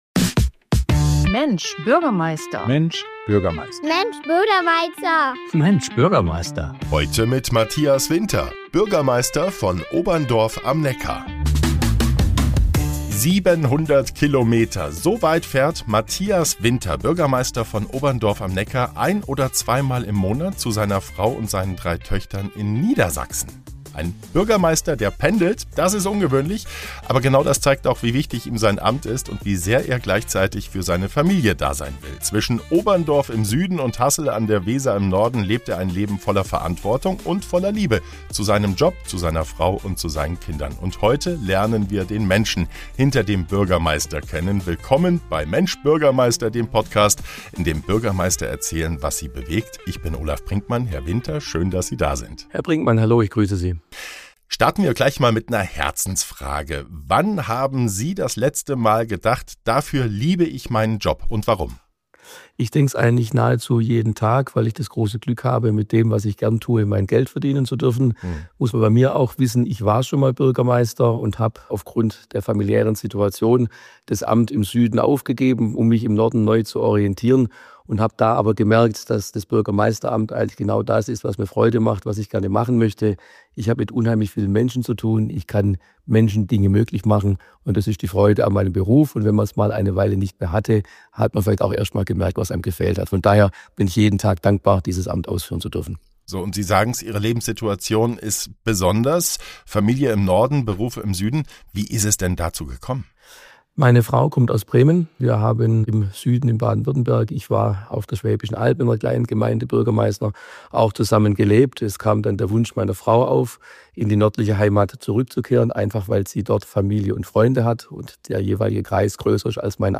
Matthias Winter pendelt regelmäßig zwischen Süddeutschland und Niedersachsen, um sowohl für seine Familie als auch für seine Stadt da zu sein. Im Gespräch erzählt er offen, warum er Bürgermeister aus Überzeugung ist, was ihn an Oberndorf begeistert und warum Begegnungen mit Menschen zu den bewegendsten Momenten seines Berufs gehören.